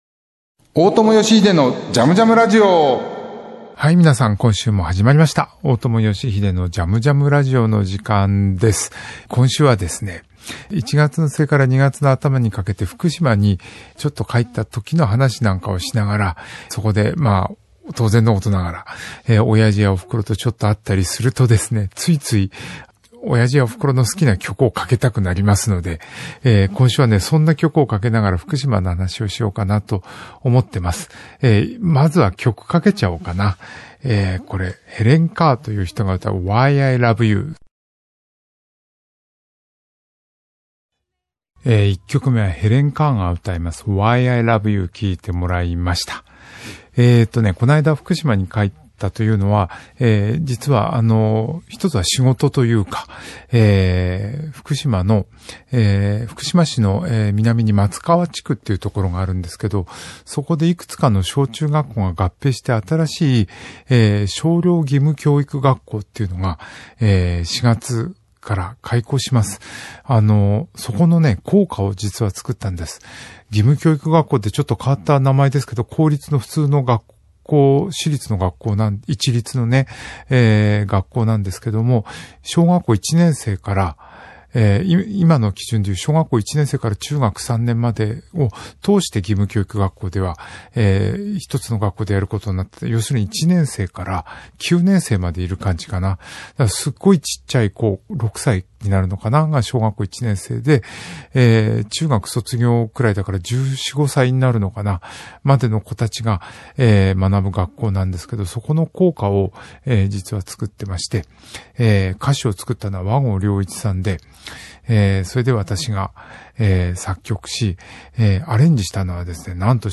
音楽家・大友良英がここでしか聞けないような変わった音楽から昔懐かしい音楽に至るまでのいろんな音楽とゲストを招いてのおしゃべりや、リスナーの皆さんからのリクエストやメッセージにもお答えしていくこの番組ならではのオリジナルなラジオ番組です。